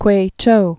(kwāchō)